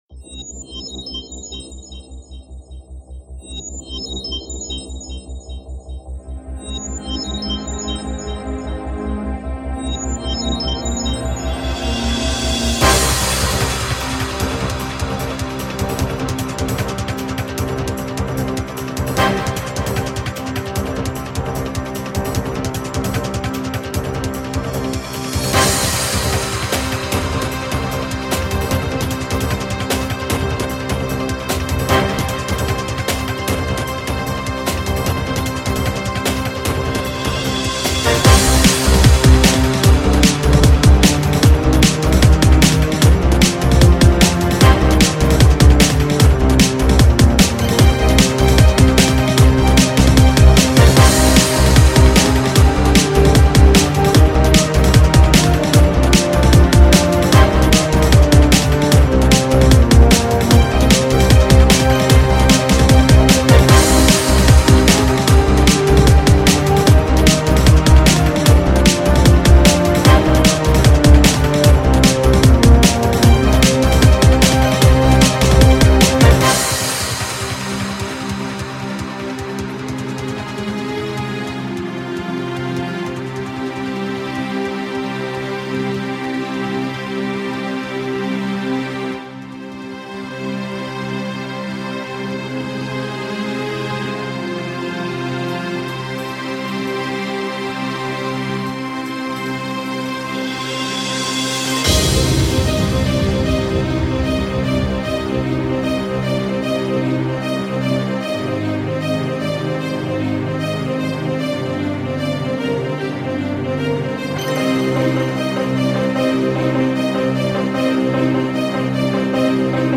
(Live mix)